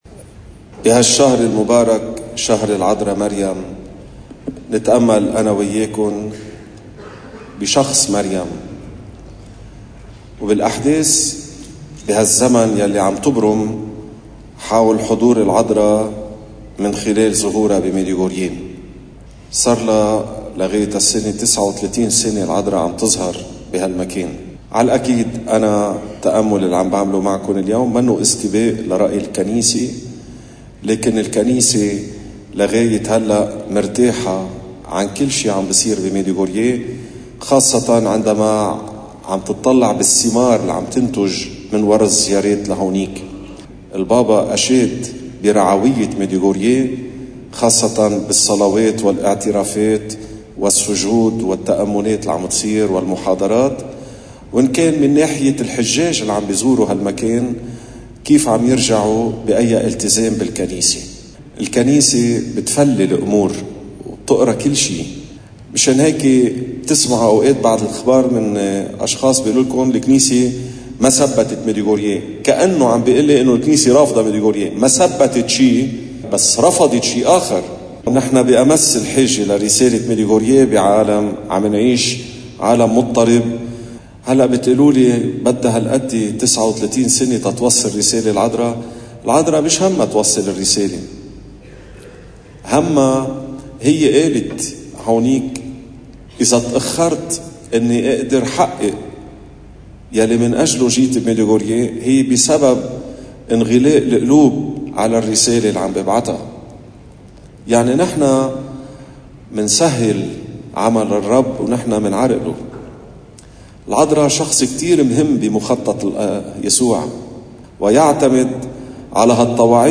مقتطف من عظة